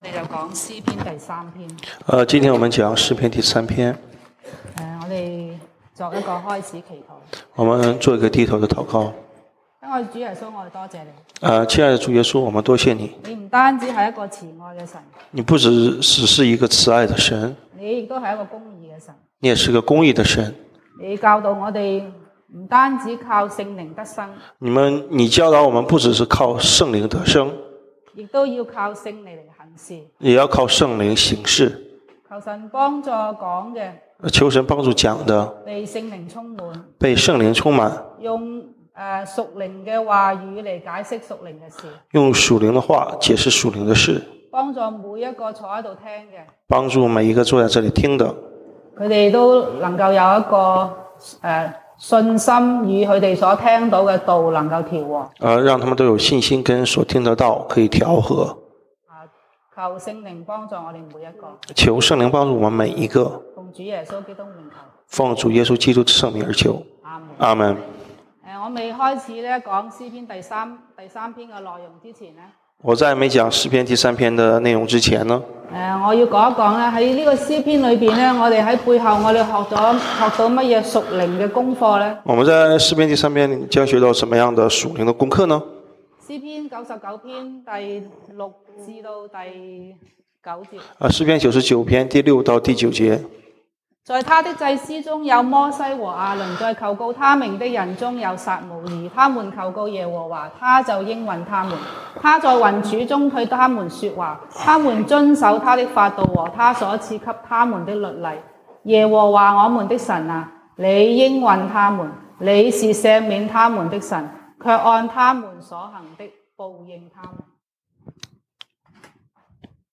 西堂證道(粵語/國語) Sunday Service Chinese: 詩篇 Psalms 3:1-8